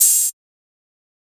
TM-88 Hats [Open Hat 1].wav